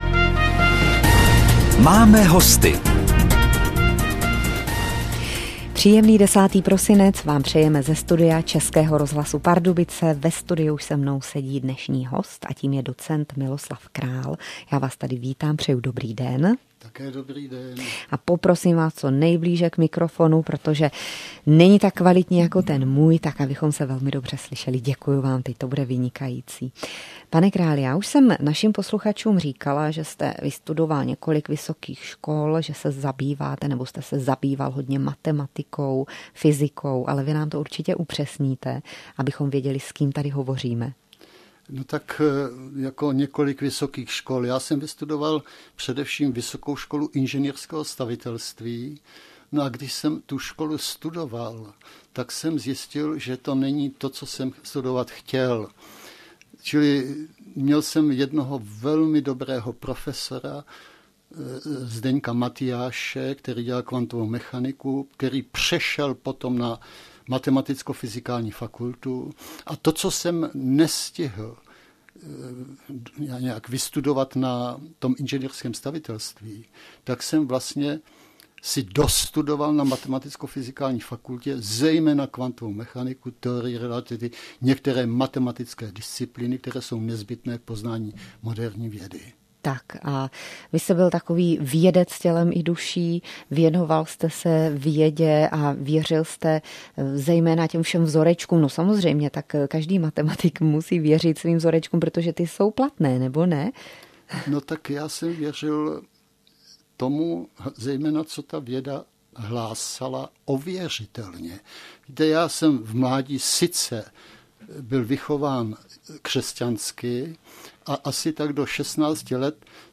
Přehrát audio Rozhlasová stanice : Český rozhlas Pardubice Pořad